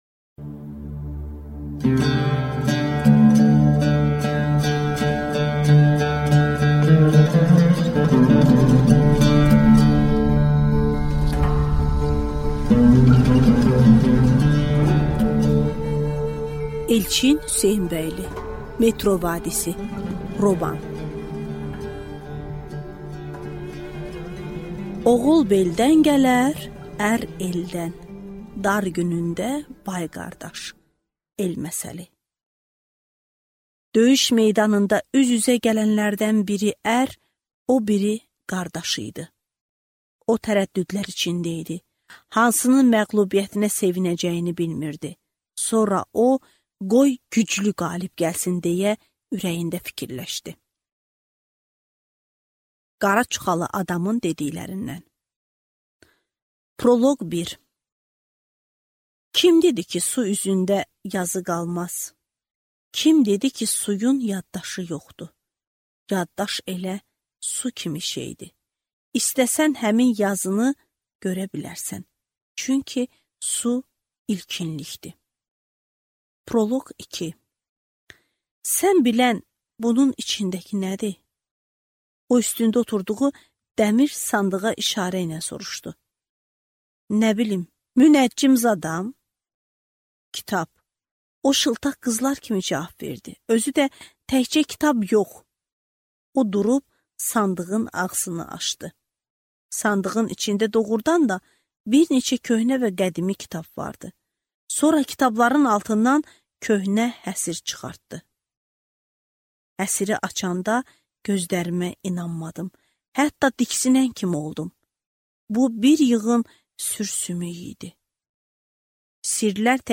Аудиокнига Metro vadisi | Библиотека аудиокниг